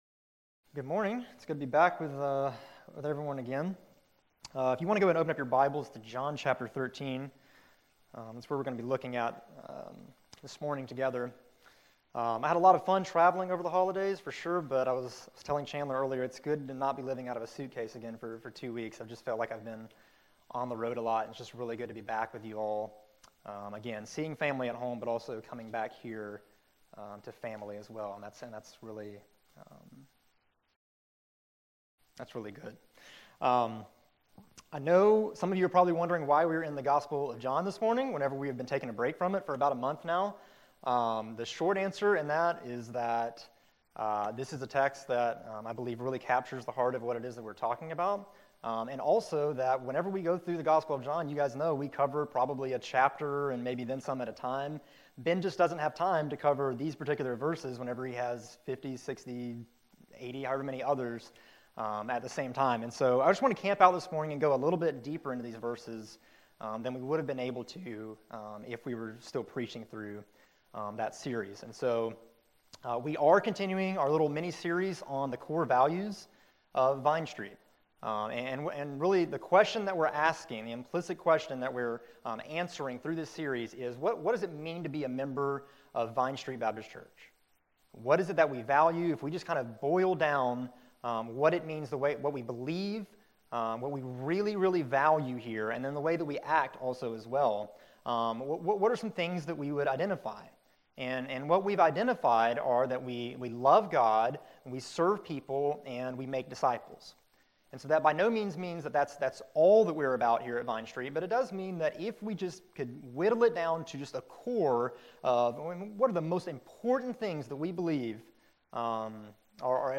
This is the second message in the Core Values sermon series.